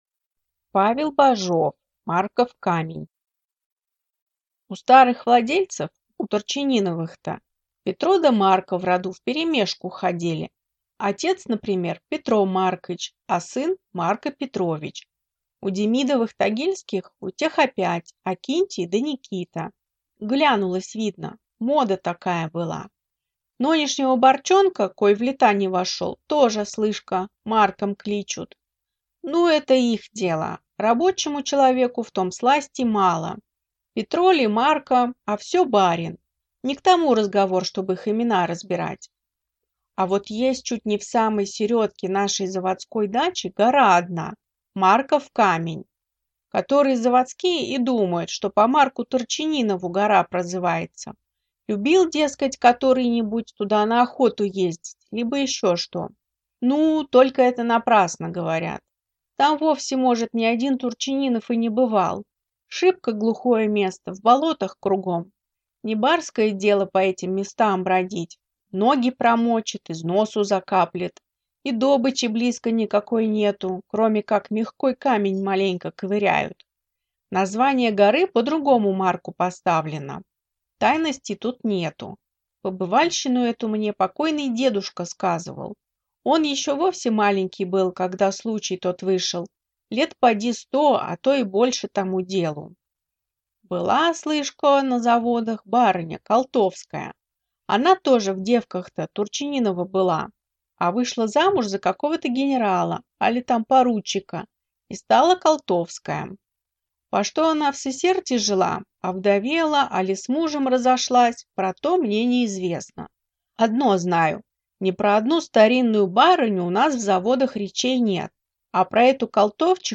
Аудиокнига Марков камень | Библиотека аудиокниг